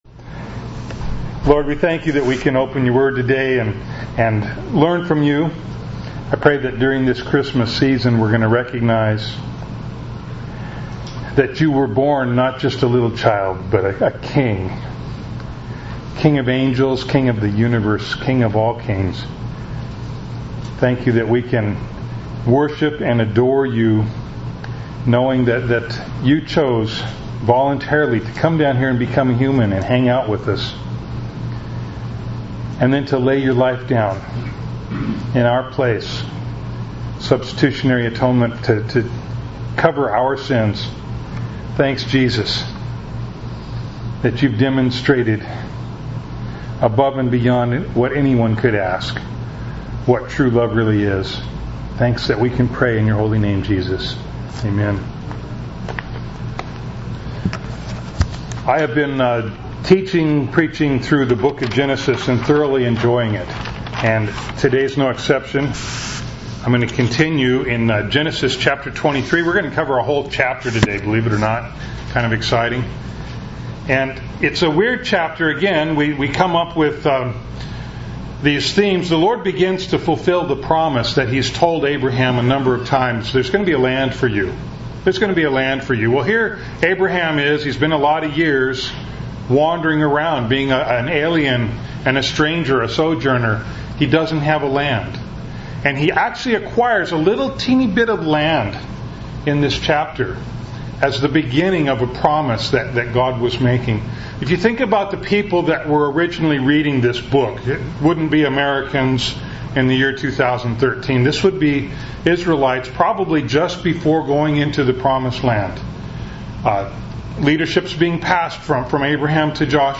Bible Text: Genesis 23:1-20 | Preacher: